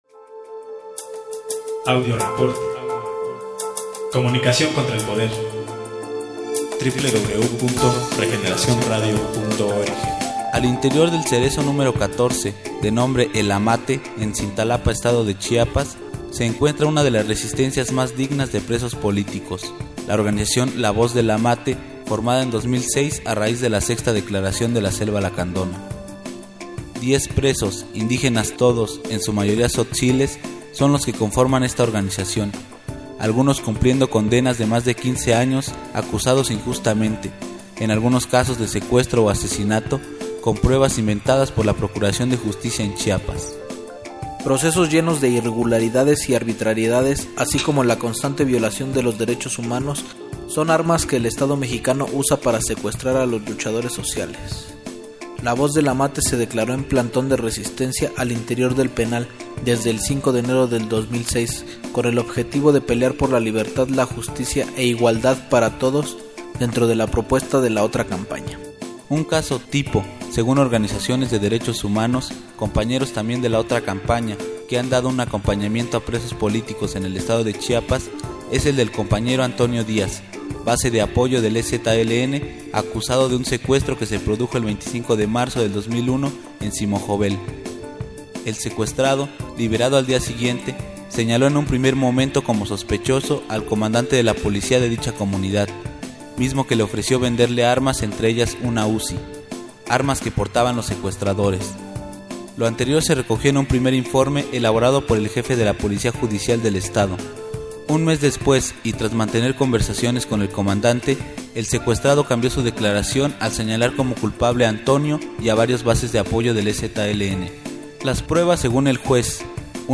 Audios del acto cultural al interior del Penal del Amate
Entrevistas con el Colectivo Ik y El Centro de DH Frayba